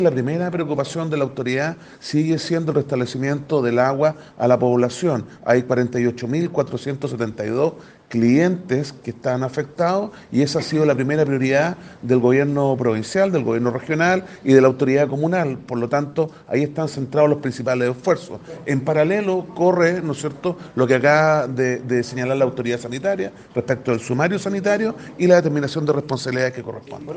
En tanto, el director regional de la Onemi en Los Lagos, Alejandro Verges, expuso que son más de 48 mil clientes afectados a causa de esta problemática.